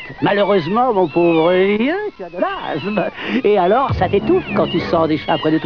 Extraits de Dialogue :